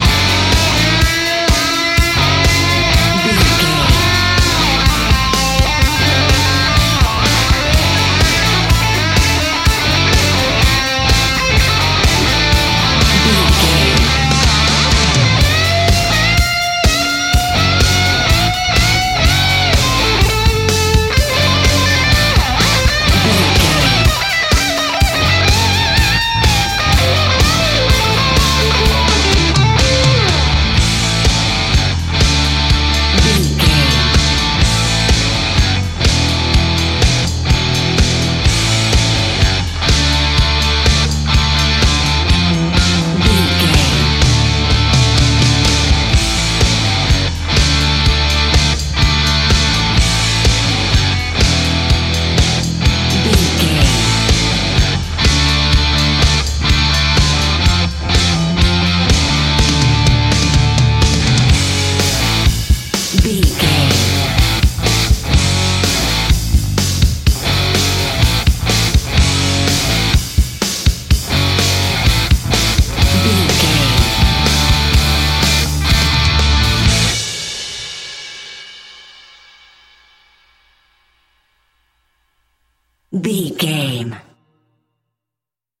Ionian/Major
F#
drums
electric guitar
bass guitar
pop rock
hard rock
lead guitar
aggressive
energetic
intense
powerful
nu metal
alternative metal